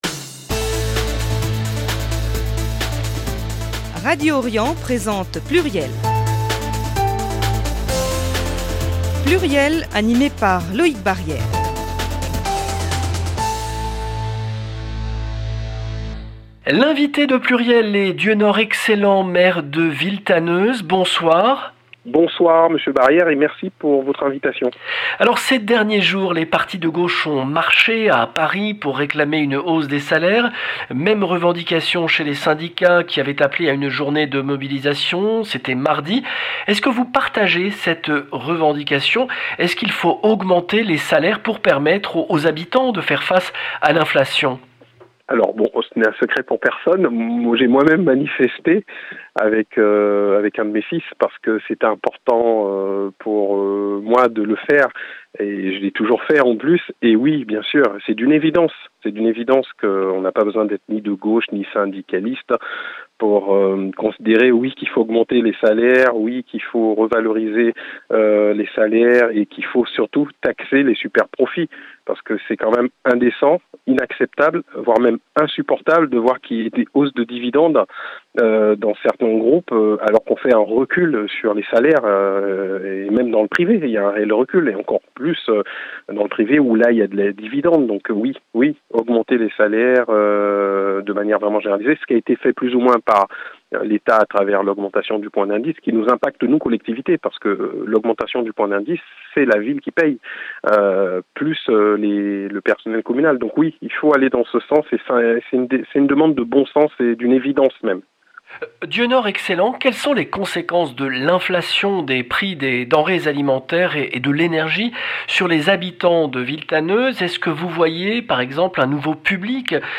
Dieunor Excellent, maire de Villetaneuse